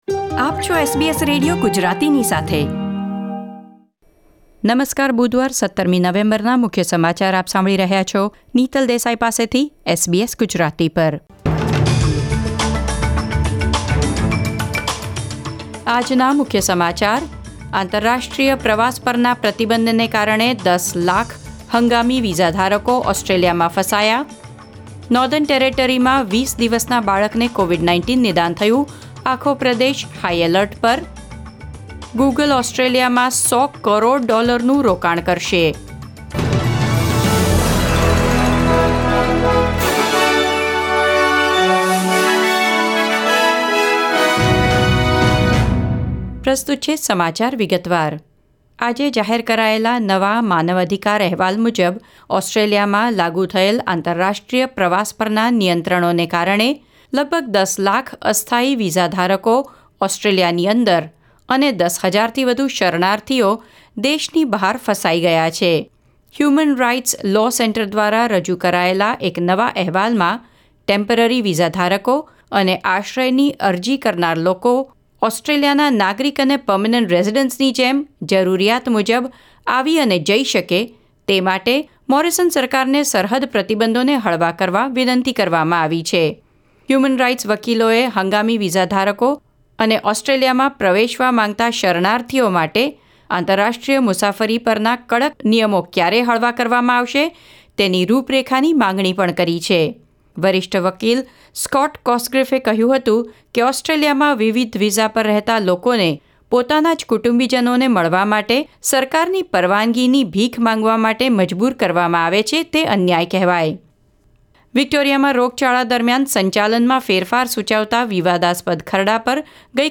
SBS Gujarati News Bulletin 17 November 2021